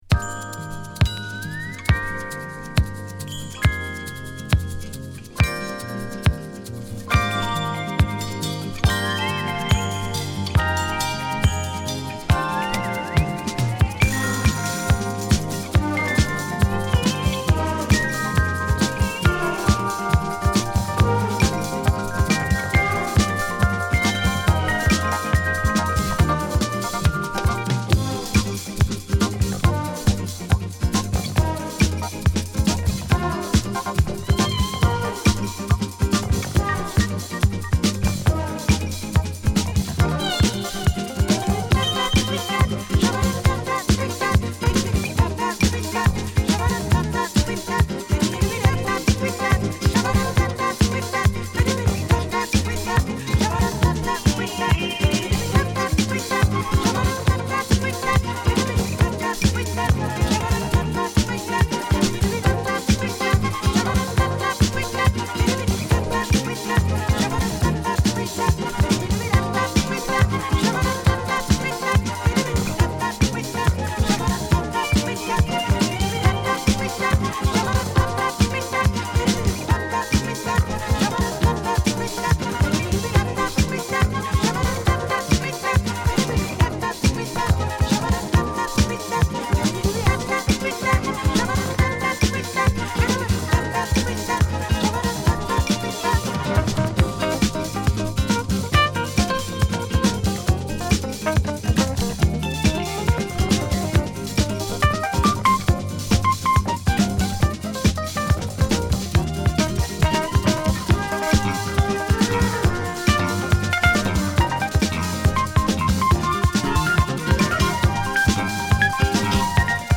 スキャットとフルートの絡み、エレピ好きならハマる事間違いなしな鍵盤プレイがのるジャジーなフュージョン・ブギー！